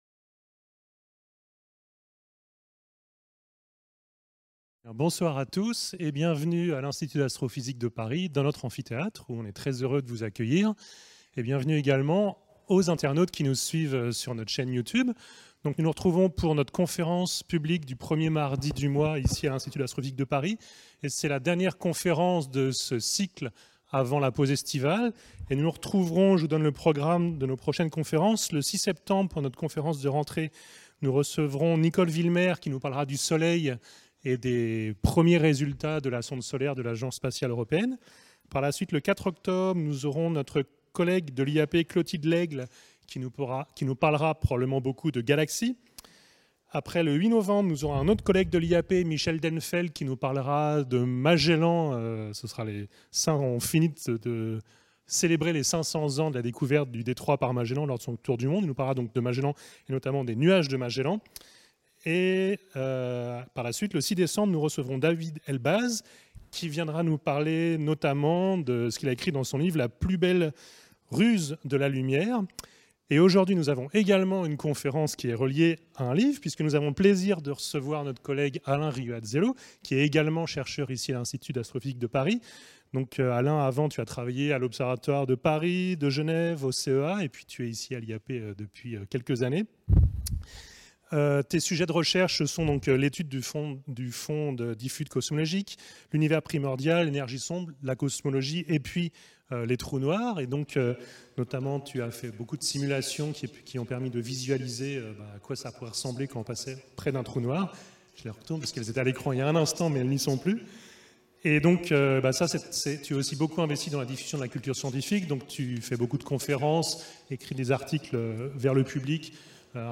Dans cette conférence, je donnerai un aperçu de quelques-unes des étapes marquantes de cette grande aventure intellectuelle, depuis l'Antiquité jusqu'à nos jours, en passant par la Renaissance et la Révolution française.